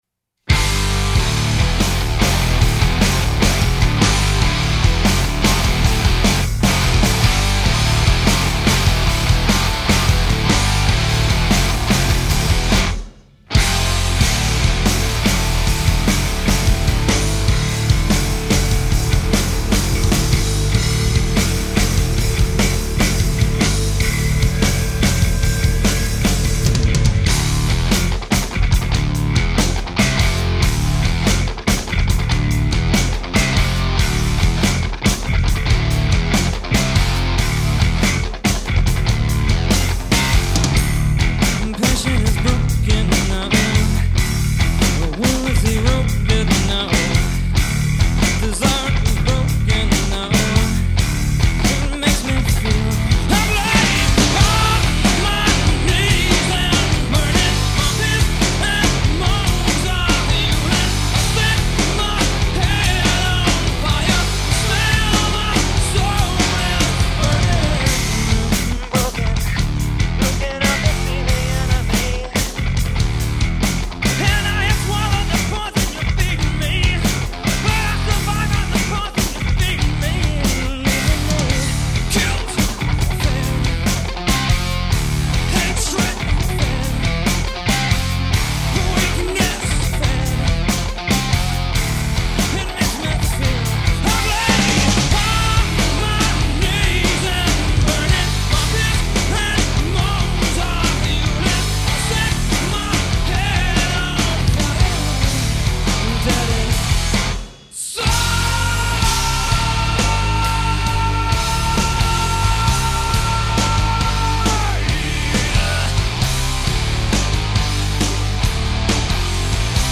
Hardrock